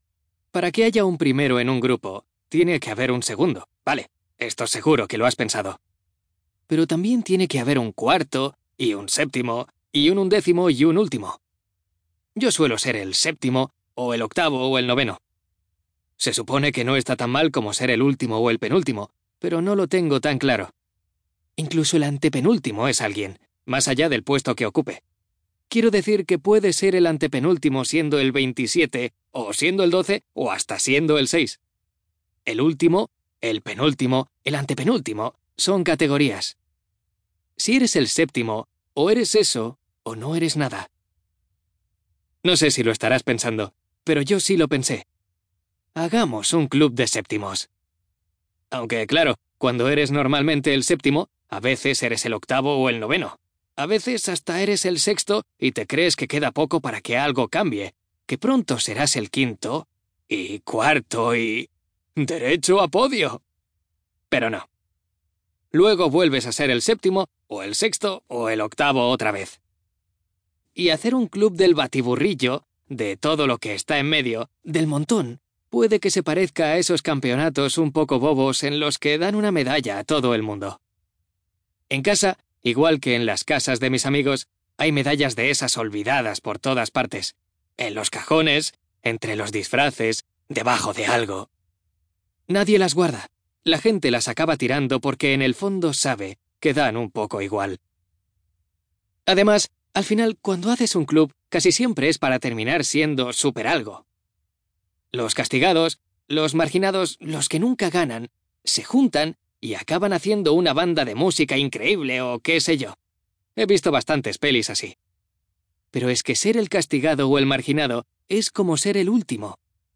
TIPO: Audiolibro CLIENTE: Audible Inc. ESTUDIO: Eclair Barcelona